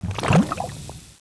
c_slime_hit1.wav